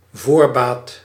Ääntäminen
IPA: [a.vɑ̃s]